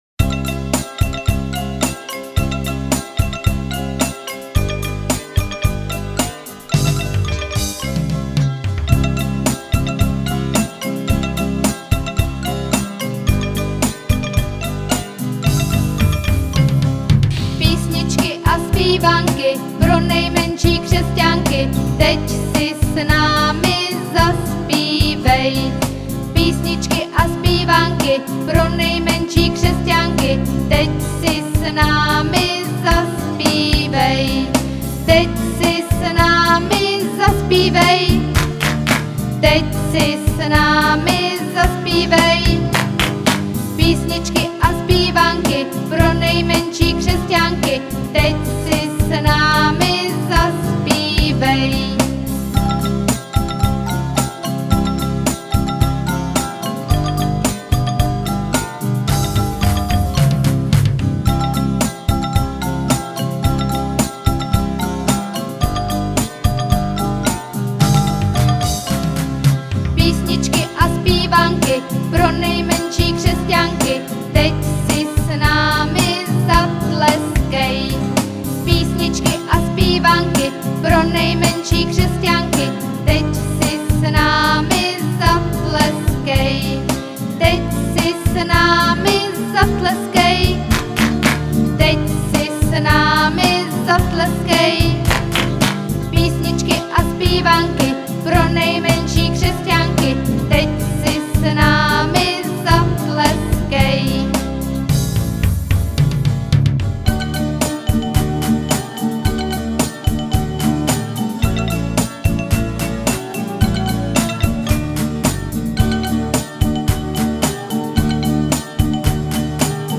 Křesťanské písně
Písničky pro děti